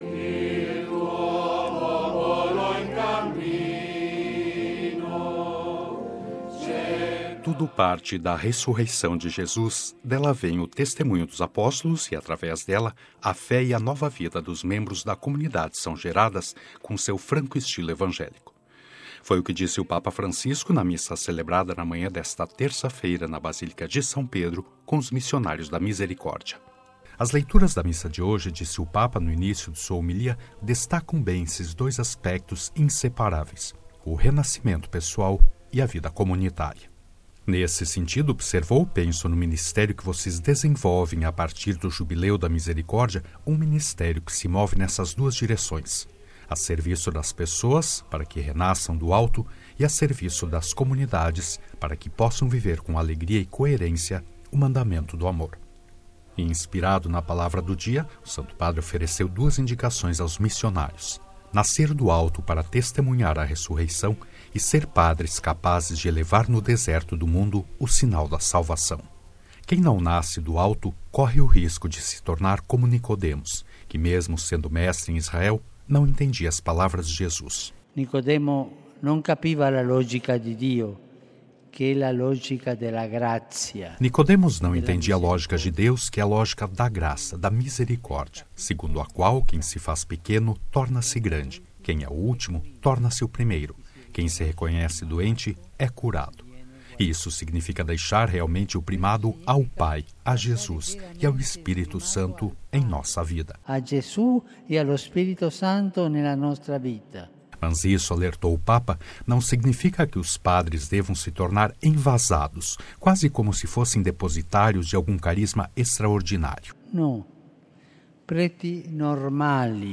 “Tudo parte da Ressurreição de Jesus: dela vem o testemunho dos apóstolos e, através dela, a fé e a nova vida dos membros da comunidade são geradas, com seu franco estilo evangélico”, disse o Papa Francisco na Missa celebrada na manhã desta terça-feira na Basílica de São Pedro, com os Missionários da Misericórdia.
Missa do Papa com os Missionários da Misericórdia